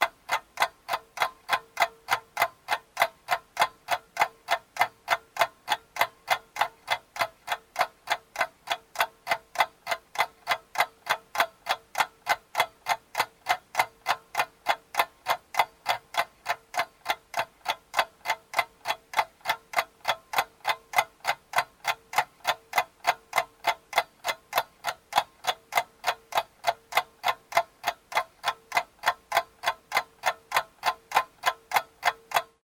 clock.ogg